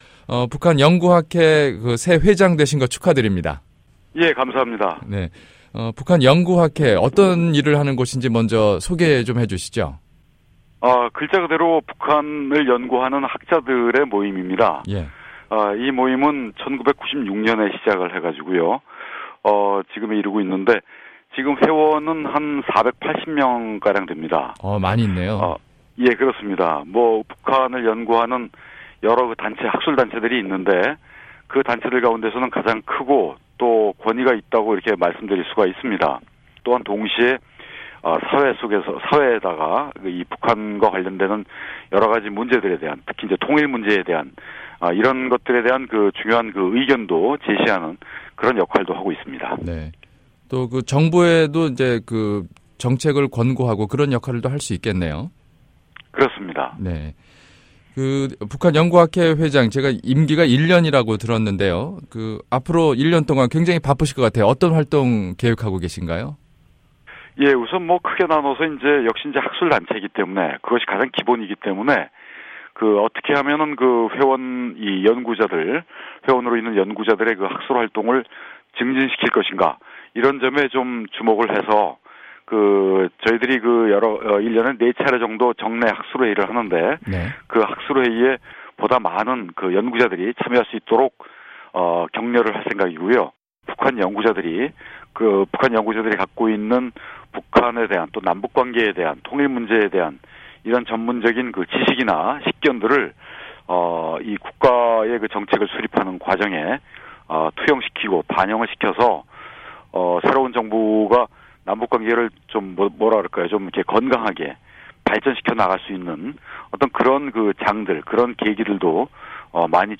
[인터뷰] 북한연구학회 신임회장 류길재 교수